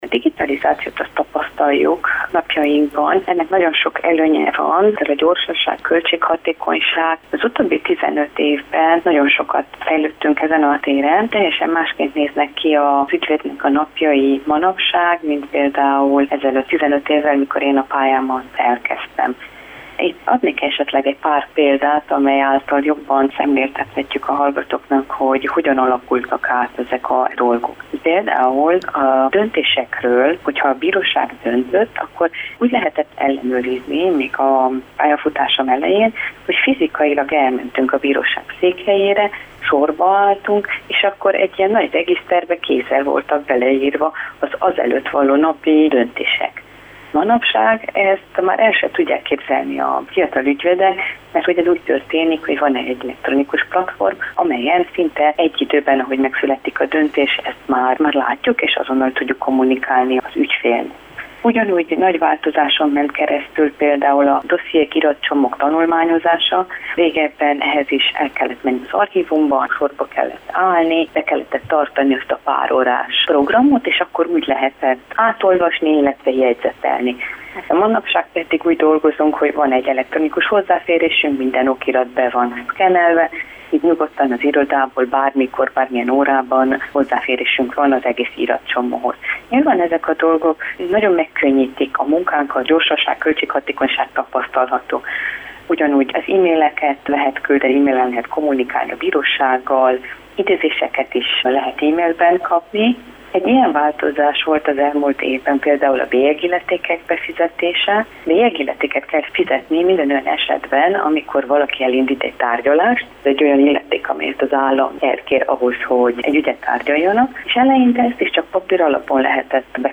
Ügyvéddel beszélgettünk az igazságszolgáltatási rendszer digitalizációjáról.